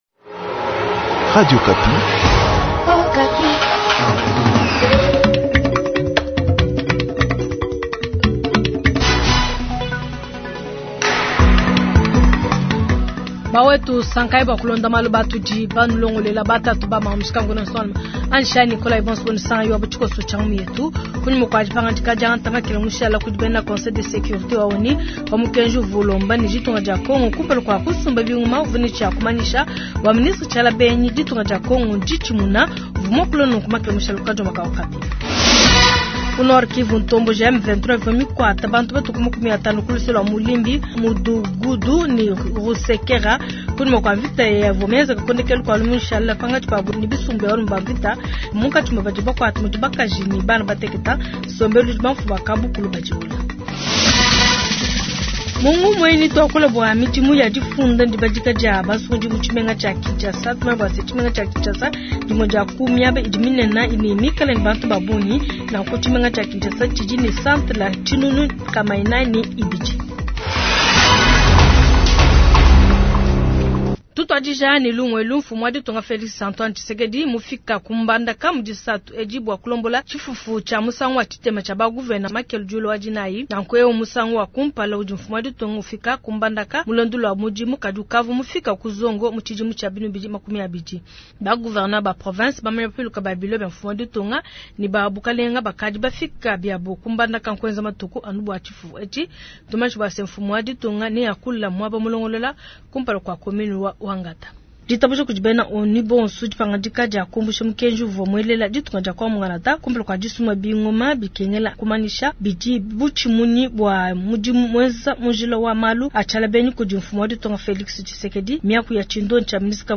Jounal soir